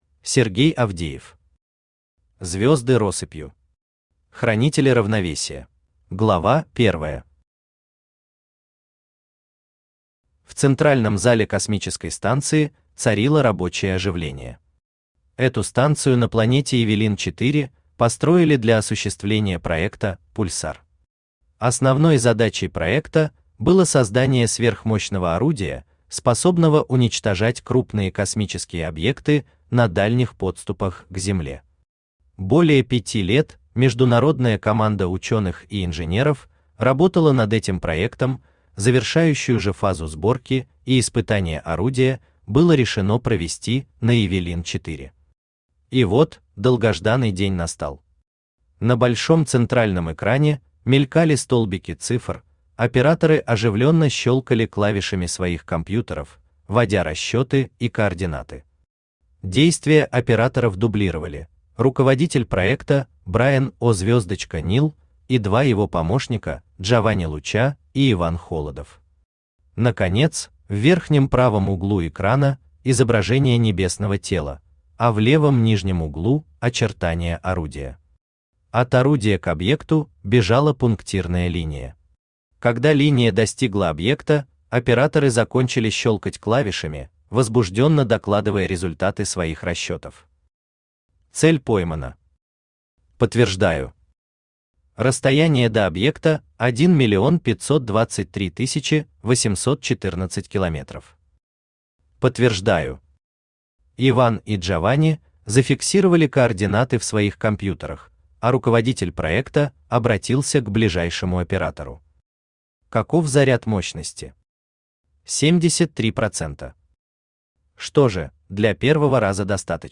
Аудиокнига Звезды россыпью. Хранители Равновесия | Библиотека аудиокниг
Хранители Равновесия Автор Сергей Дмитриевич Авдеев Читает аудиокнигу Авточтец ЛитРес.